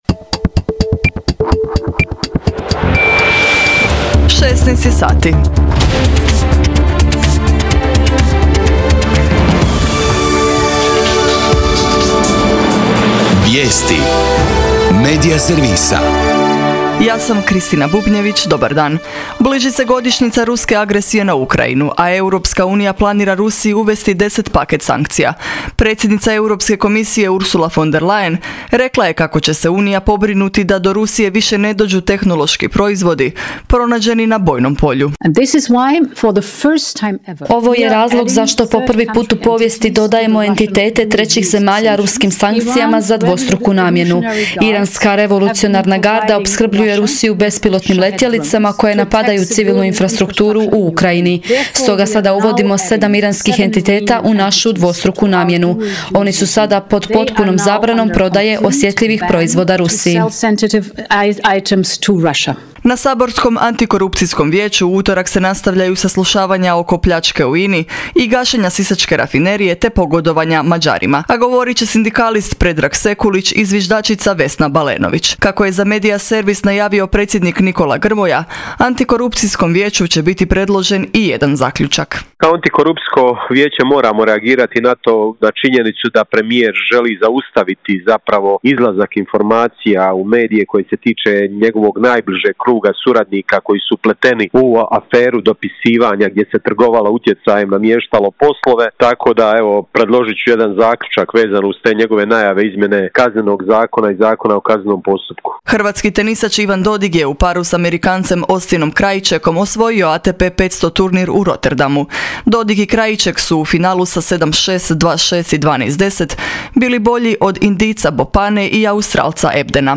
VIJESTI U 16